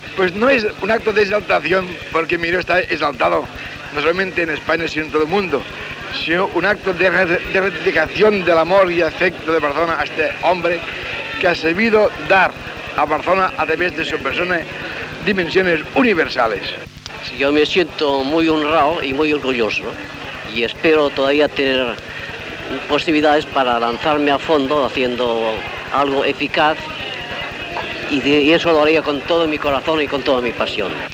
Paraules de l'alcalde de Barcelona José María de Porcioles i del pintor Joan Miró en el descobriment d'una placa al Passatge del Crèdit amb motiu del seu 75è aniversari i de l'Any Mundial Miró.
Informatiu